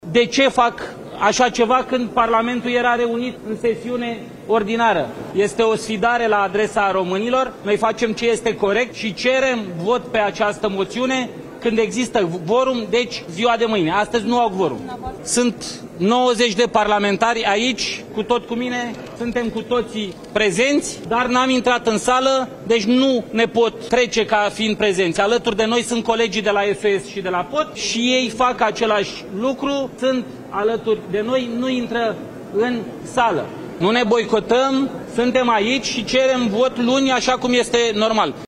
Înainte de începerea ședinței, liderul AUR, George Simion, a anunțat, pe holurile Parlamentului, că parlamentarii opoziției nu vor intra în sala de plen și a solicitat ca votul moțiunilor să aibă loc mâine.